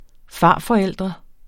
Udtale [ ˈfɑˀ- ]